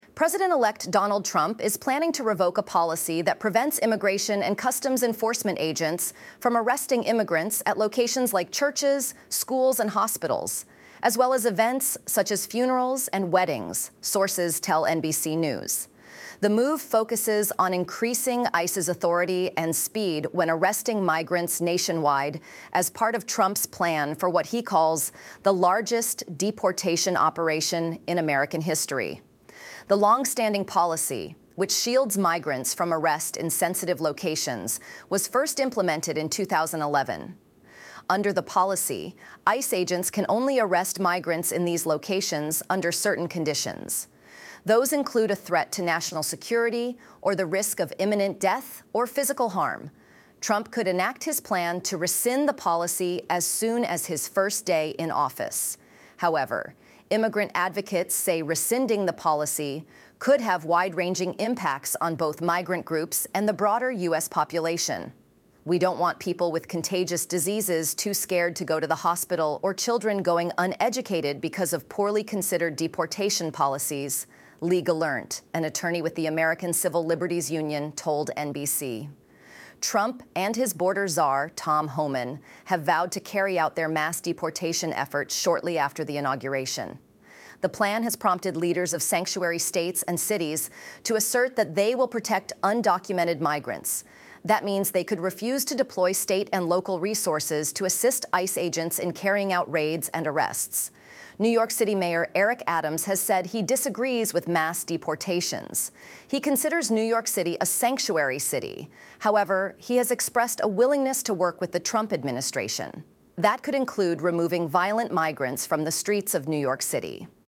This recording was made using enhanced software.